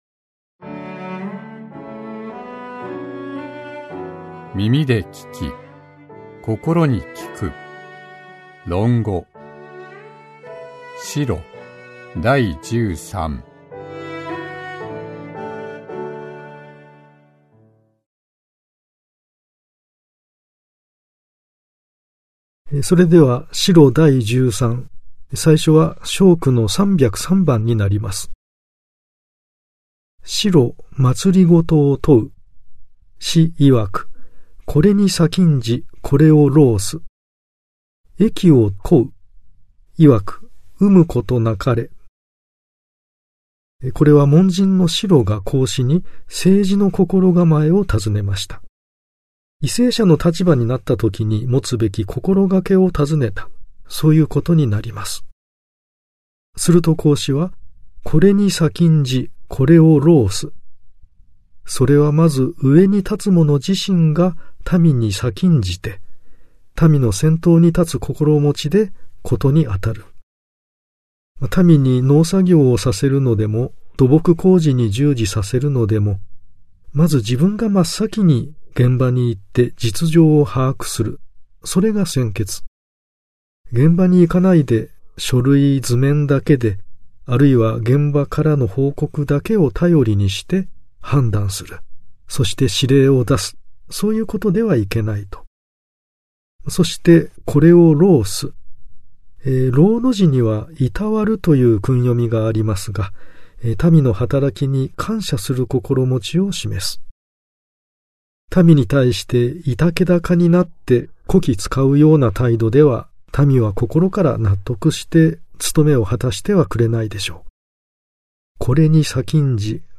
[オーディオブック] 耳で聴き 心に効く 論語〈子路第十三〉
講師